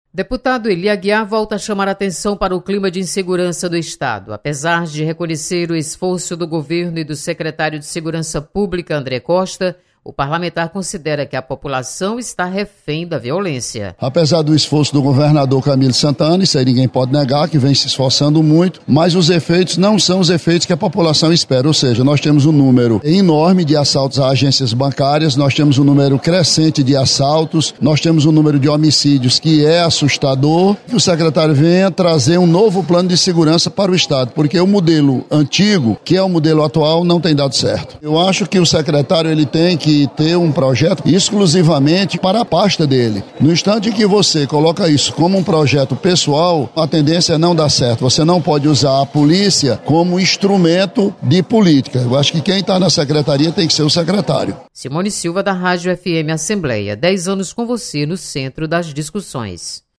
Deputado Ely Aguiar mostra preocupação com a violência no Ceará. Repórter